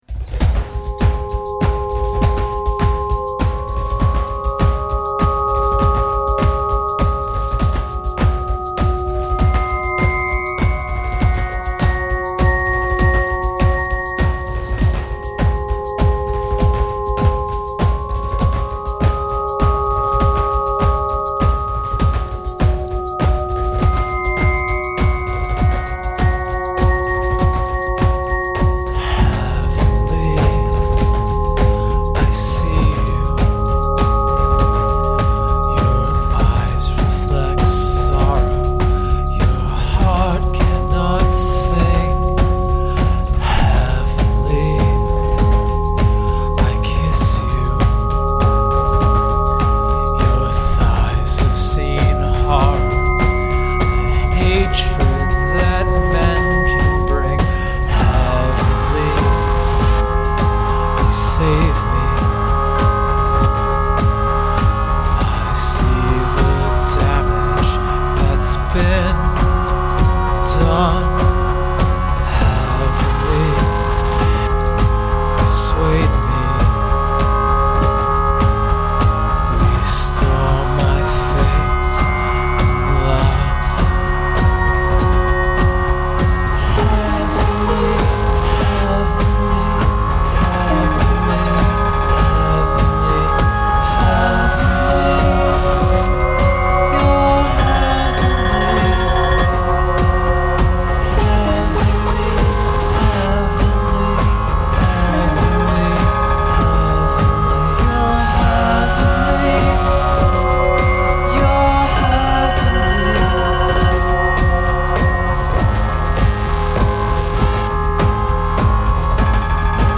Pop so dense it's got it's own entry on the periodic table.